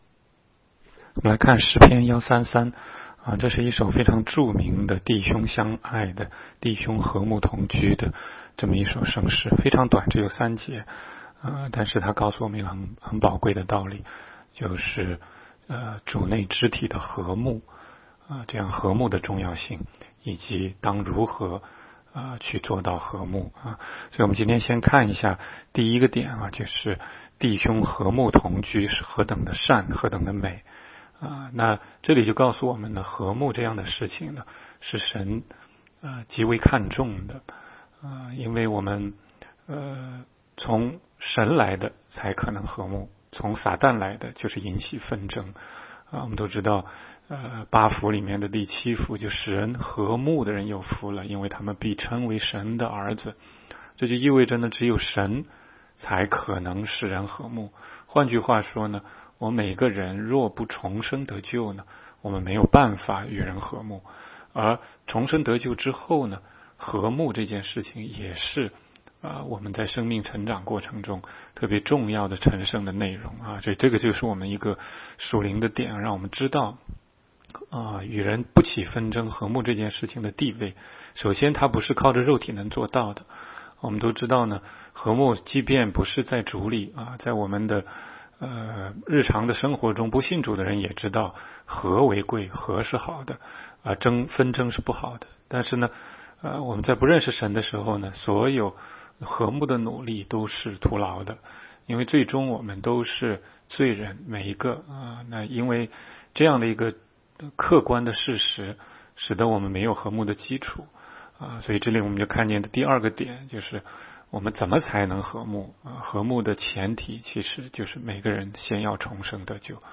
16街讲道录音 - 每日读经 -《 诗篇》133章